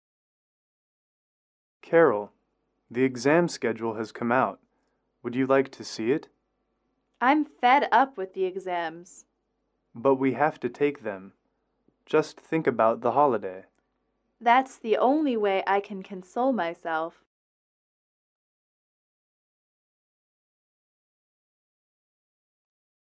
英语口语情景短对话30-4：厌倦了考试（MP3）